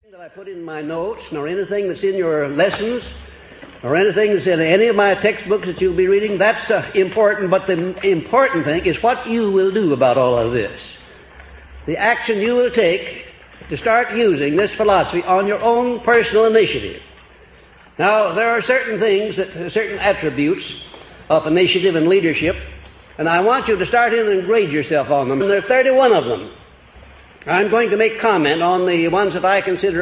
The lost recordings of Napoleon Hill are lectures as he personally promotes his philosophy of wealth and achievement; written about in his famous books Law of Success and Think and Grow Rich.